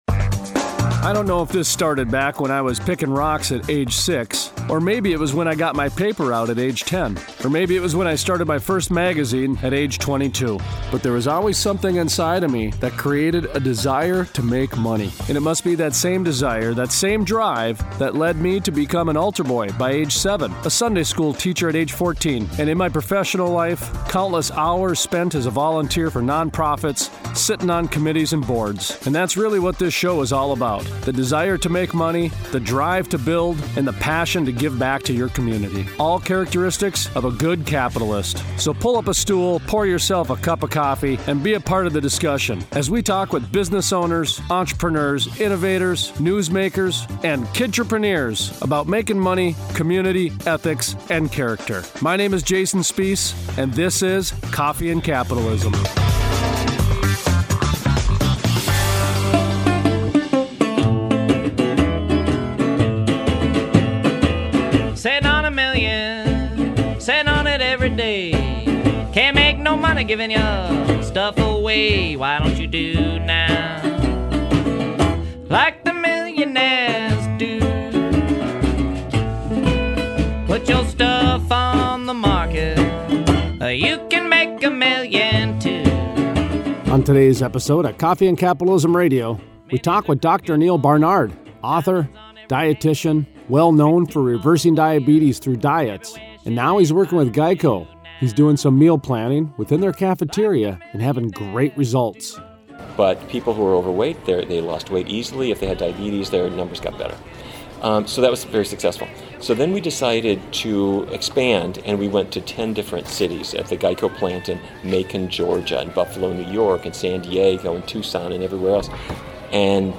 Interview: Mark Speirs, Commercial Painter, City Commissioner, Deadwood SD Talks about how difficult it has been historically making a living in the Black Hills.